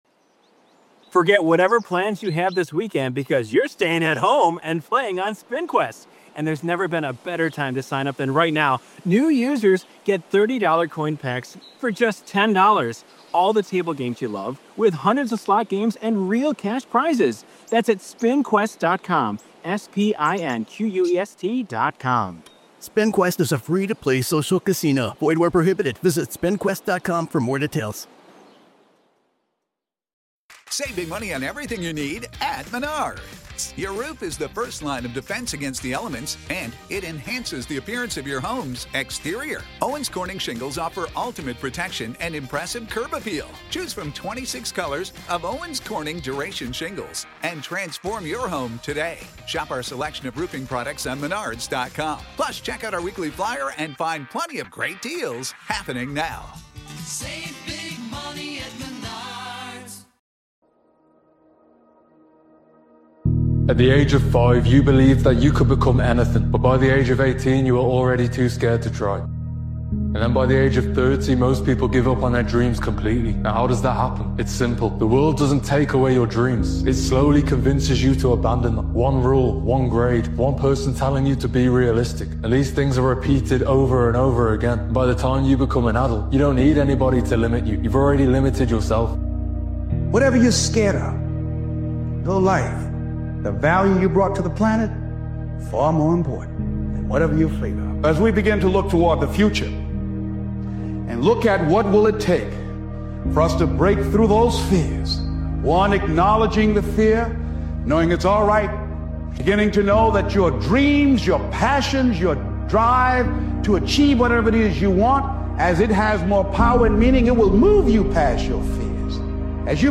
This powerful motivational speeches compilation is a declaration of intent—setting the standard early and committing to the work it takes to earn it. 2026 i...